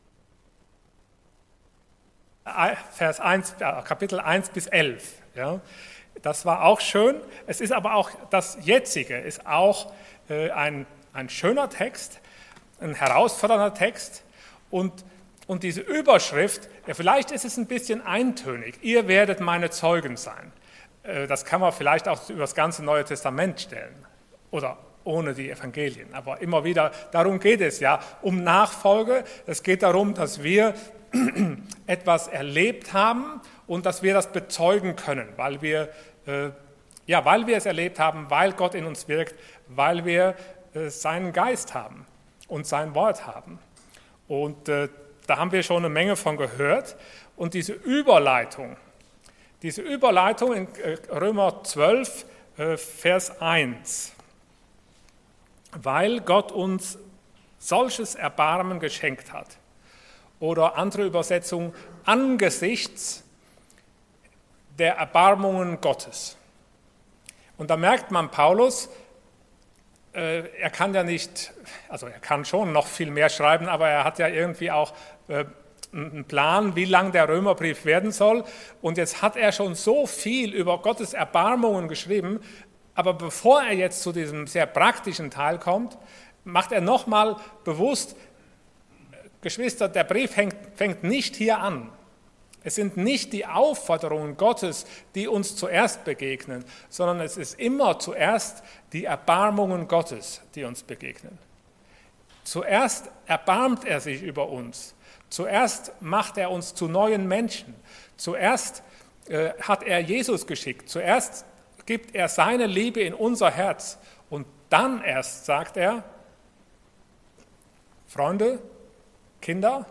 Passage: Römer 12,9-13 Dienstart: Sonntag Morgen